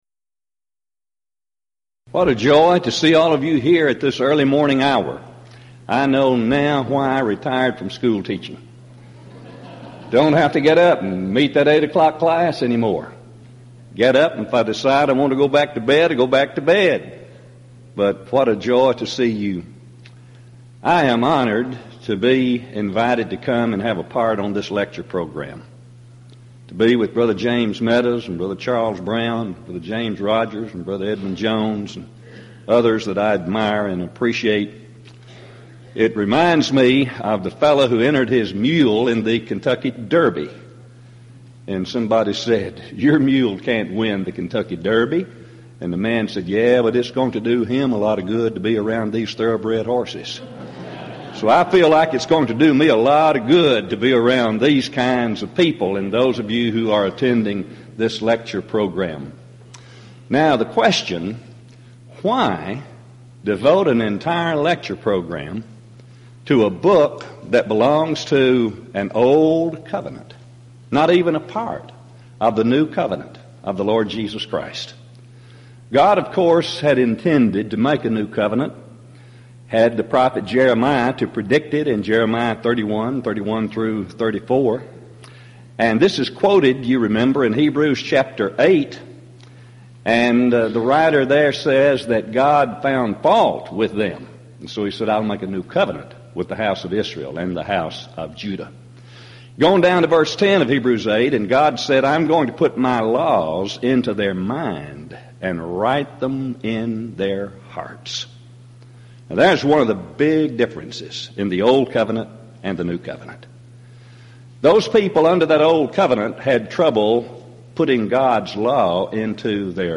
Event: 1998 East Tennessee School of Preaching Lectures Theme/Title: Studies in the Book of Leviticus
lecture